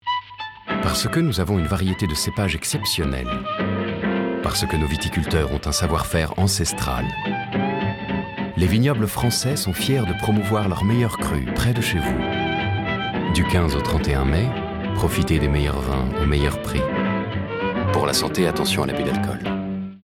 Voix Off, voix masculine, homme, voix grave, voix très grave, voix chaude, voix paternelle, voix sécurisante, voix douce, voix puissante.
Sprechprobe: Industrie (Muttersprache):